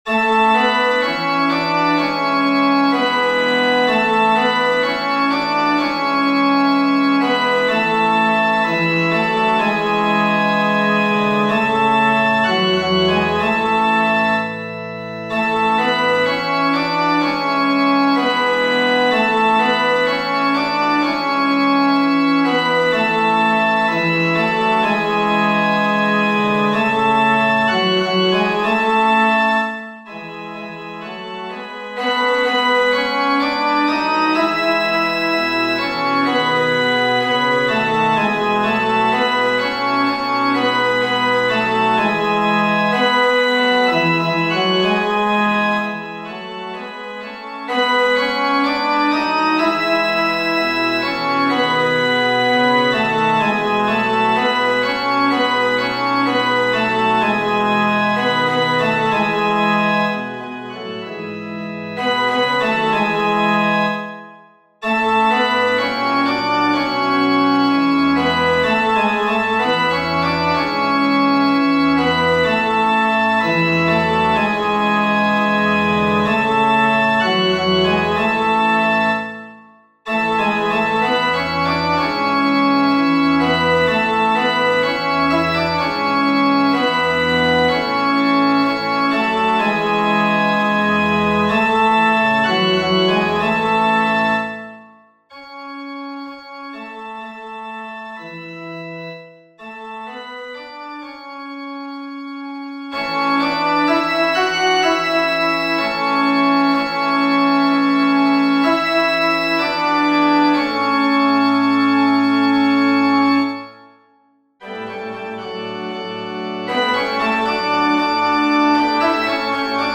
FF:HV_15b Collegium musicum - mužský sbor